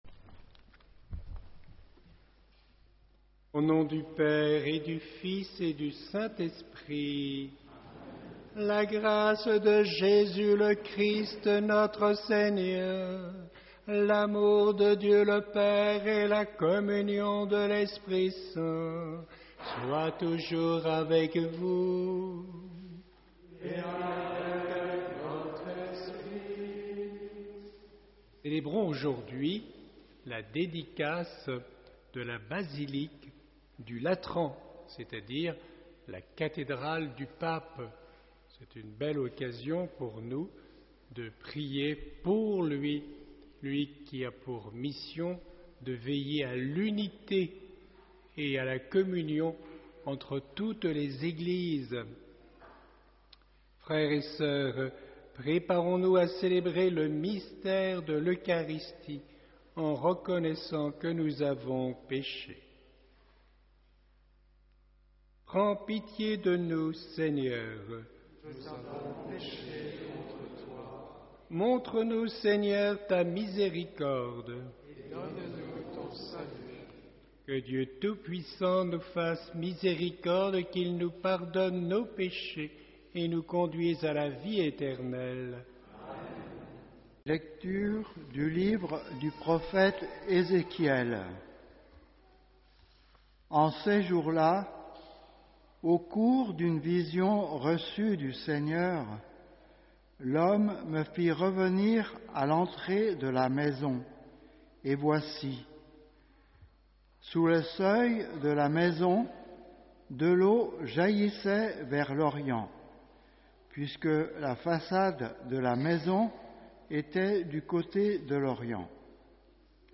Toute la célébration est disponible sur Youtube HOMELIE : Le principe de gratuité La foi n’est pas un commerce.